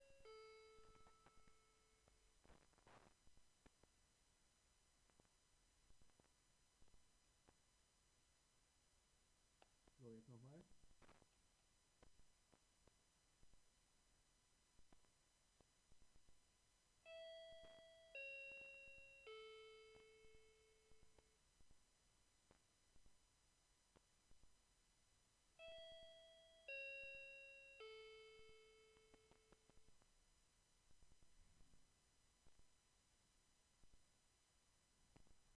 klingelton as wav
klingel_aufnahme_microphne.wav